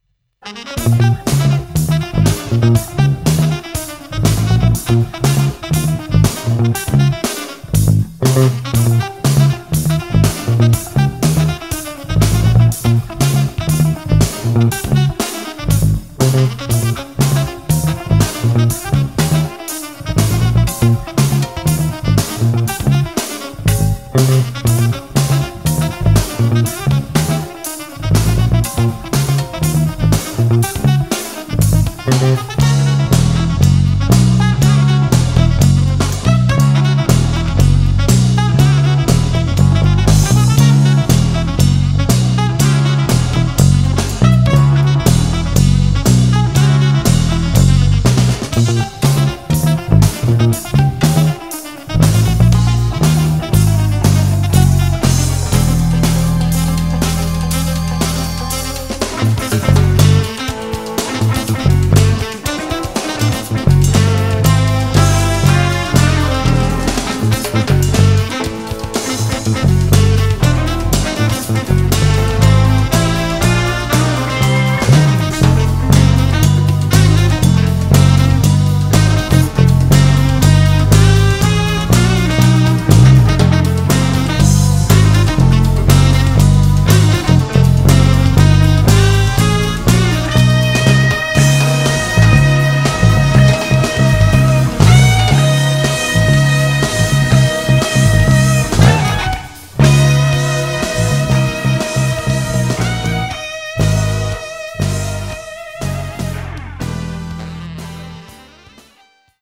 Bicentennial 1976 Bass Pickup in Mix